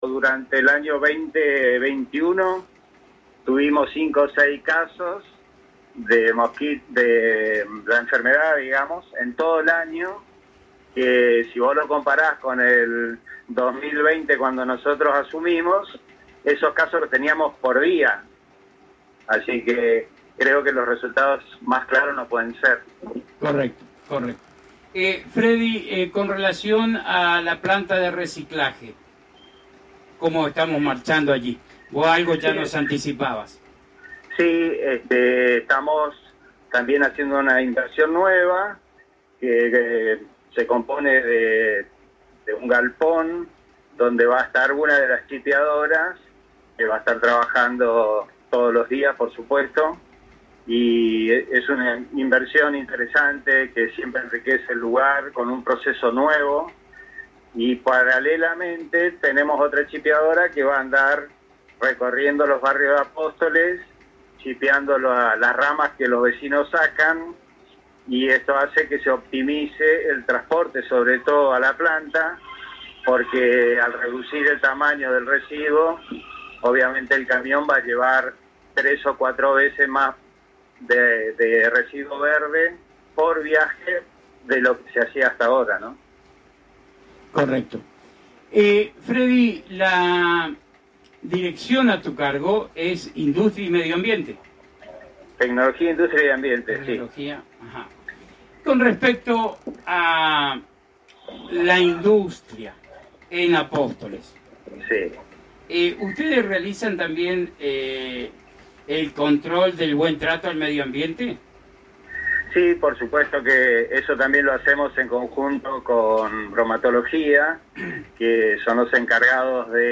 En entrevista exclusiva con la ANG y FM Éxito el Secretario de Tecnología, Industria y Medio Ambiente Alfredo «Fredy» Rau manifestó que se han comenzado los trabajos de descacharrización en la Ciudad de Apóstoles comenzando por los barrios más antiguos continuando por toda la Ciudad.